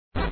slide.ogg